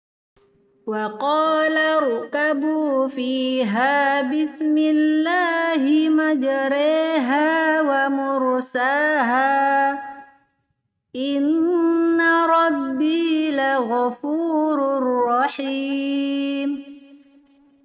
Imalah yaitu membaca huruf yang berharakat Fattah dimiringkan ke harakat kasrah, sehingga menjadi bunyi, contoh Surah Hud ayat 41 .